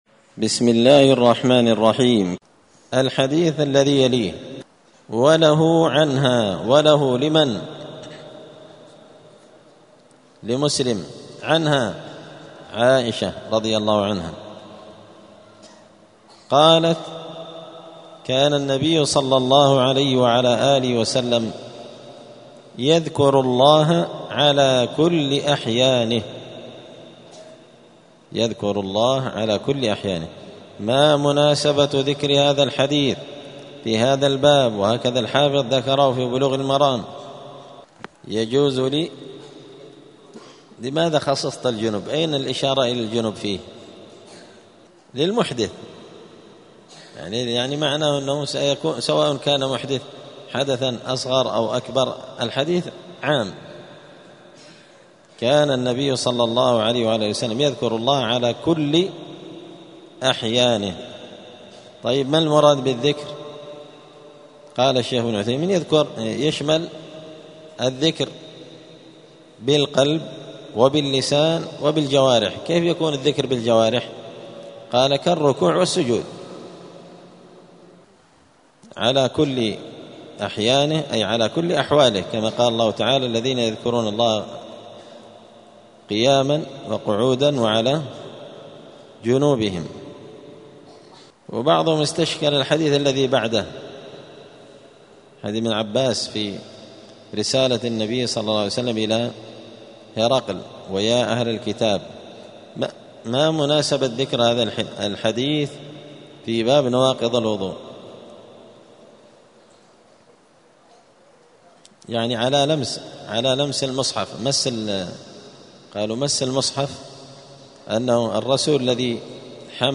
دار الحديث السلفية بمسجد الفرقان قشن المهرة اليمن
*الدرس الثامن والخمسون [58] {باب ما ينقض الوضوء حكم قراءة القرآن للمحدث}*